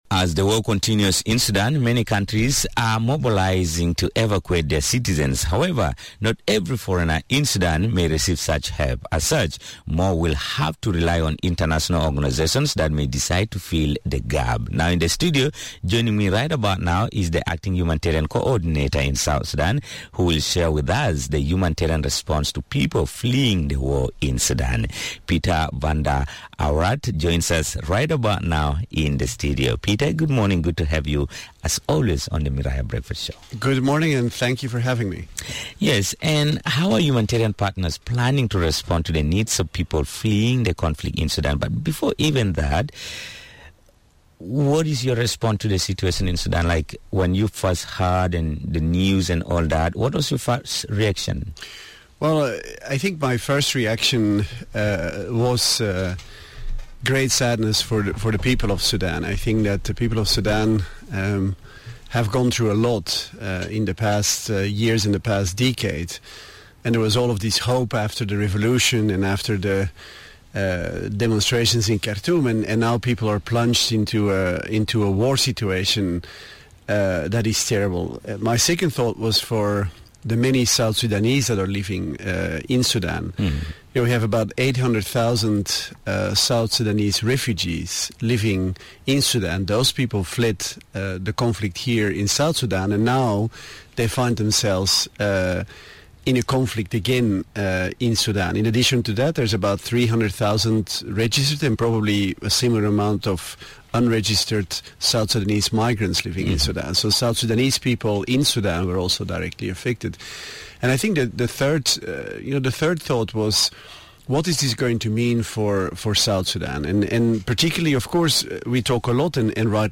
Thousands of civilians are fleeing the conflict in Sudan, the acting UN Humanitarian Coordinator and Chief of IOM missionin South Sudan spoke in an interview with Radio Miraya about the assistance provided for hundreds of returnees and refugees.